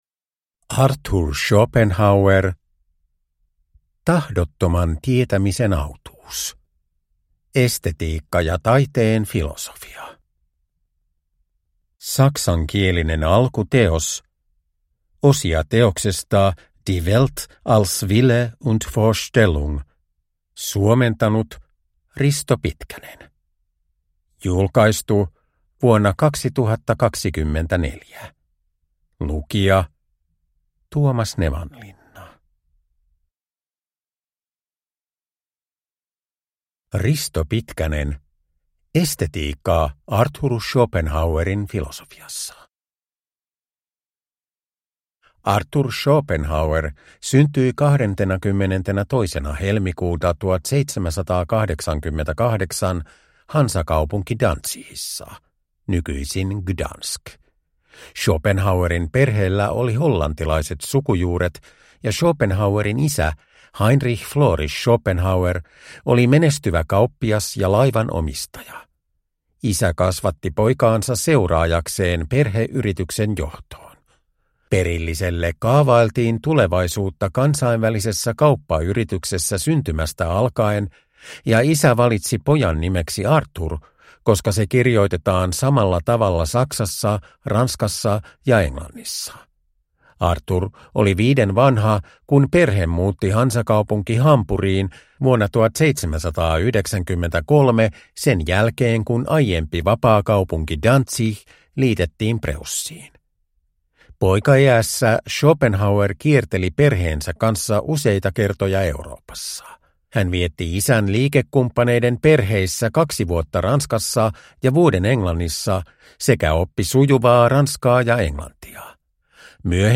Uppläsare: Tuomas Nevanlinna
Ljudbok